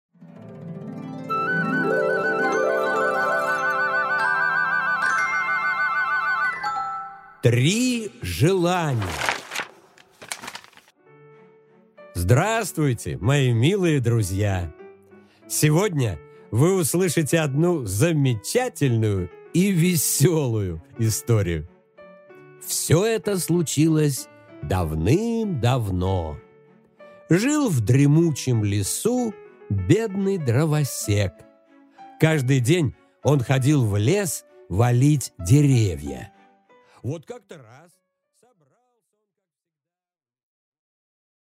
Аудиокнига Три желания